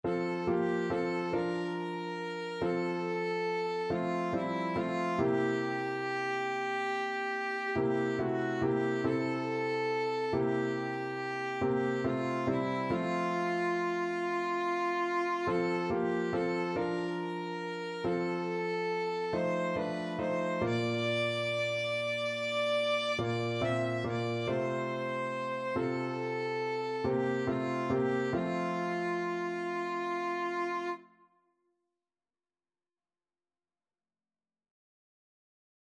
9/4 (View more 9/4 Music)
Classical (View more Classical Violin Music)